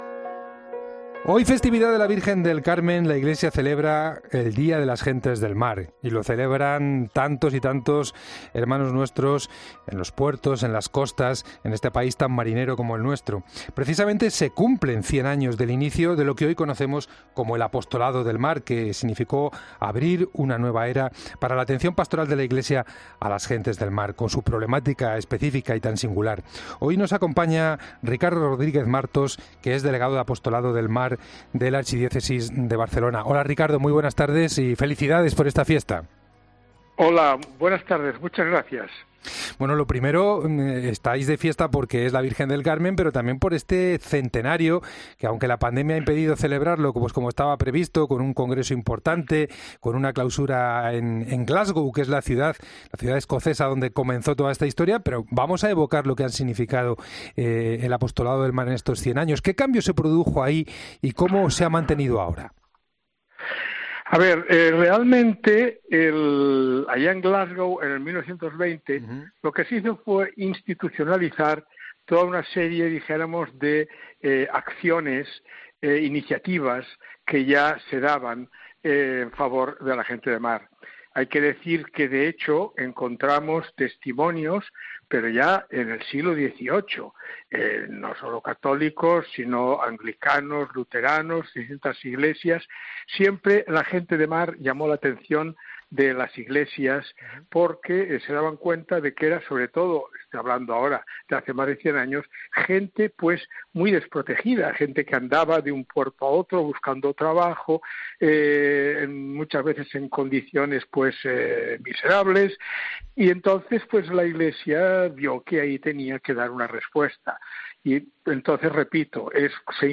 Este Apostolado supuso una nueva etapa de atención a todas las personas que dedican su vida al mar. Esta mañana ha pasado por los micrófonos de la Cadena COPE